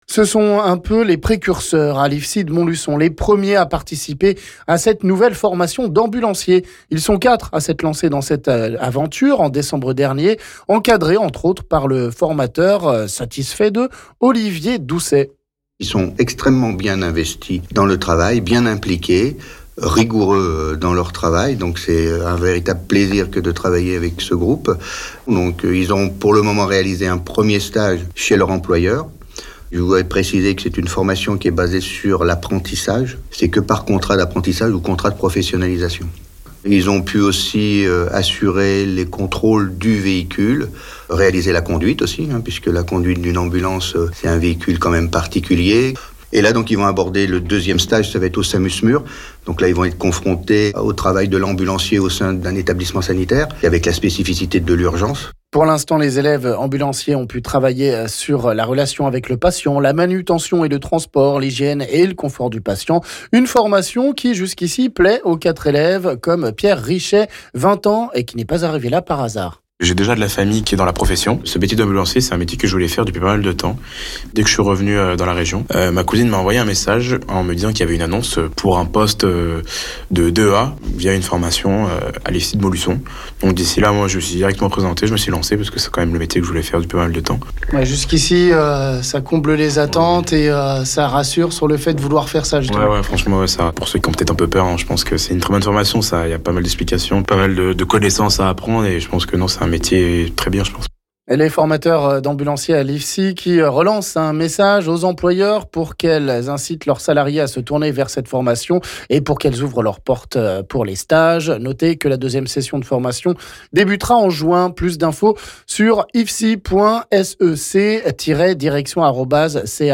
On écoute un formateur et un élève...